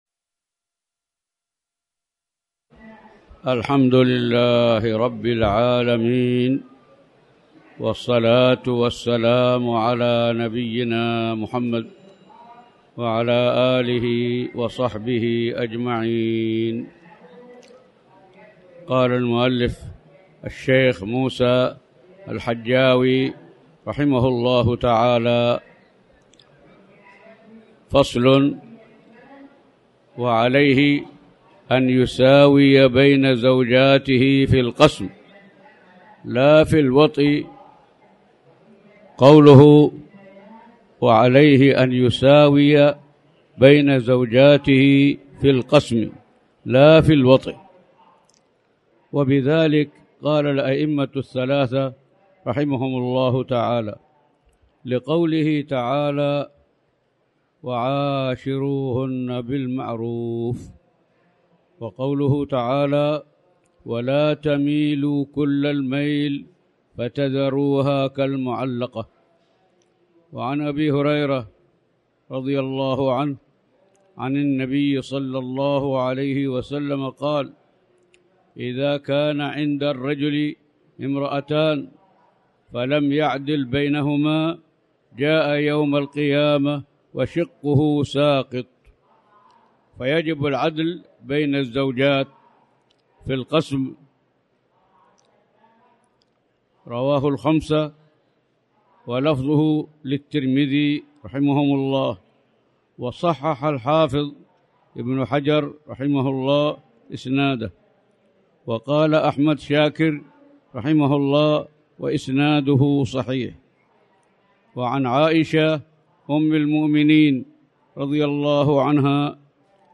تاريخ النشر ١ شعبان ١٤٣٩ هـ المكان: المسجد الحرام الشيخ